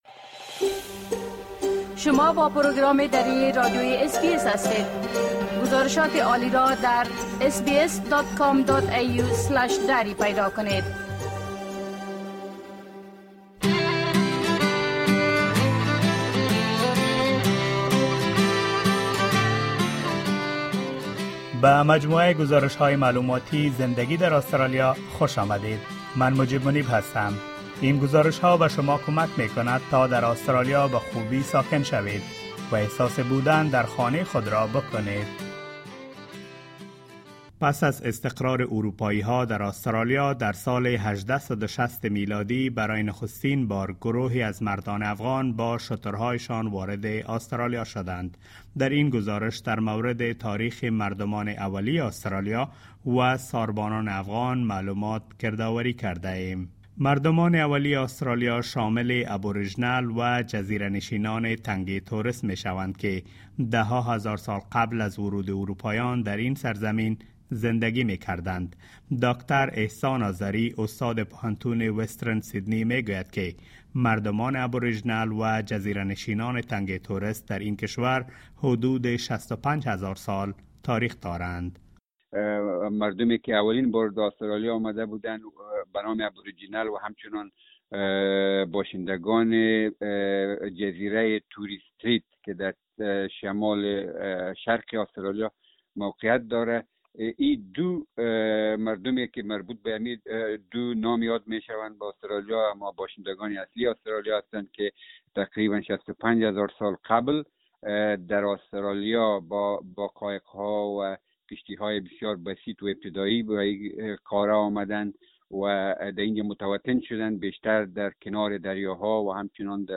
After the European settlement in Australia, the Afghan cameleers were brought to Australia between the 1860s and 1930 to help the continent's settlers trek the outback. In this informative report, we have gathered information about the history of first people and the Afghan Cameleers.